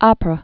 prə)